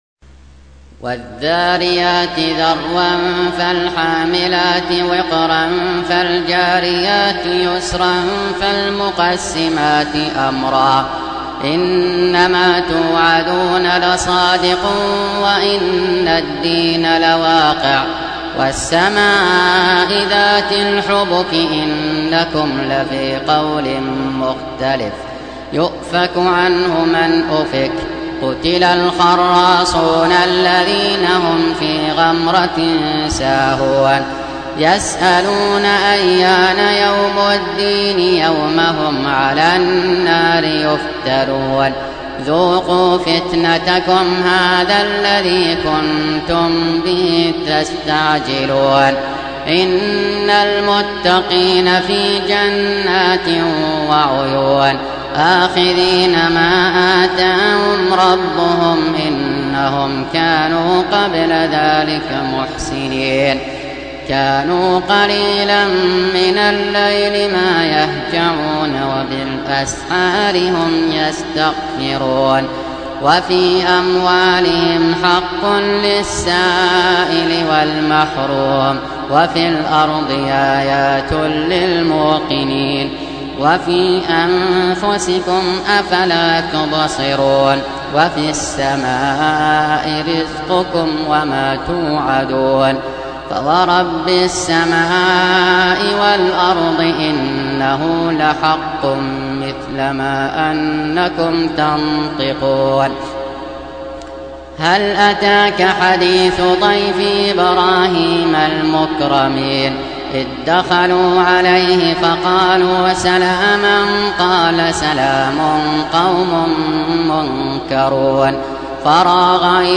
51. Surah Az-Z�riy�t سورة الذاريات Audio Quran Tarteel Recitation
حفص عن عاصم Hafs for Assem
Surah Repeating تكرار السورة Download Surah حمّل السورة Reciting Murattalah Audio for 51. Surah Az-Z�riy�t سورة الذاريات N.B *Surah Includes Al-Basmalah Reciters Sequents تتابع التلاوات Reciters Repeats تكرار التلاوات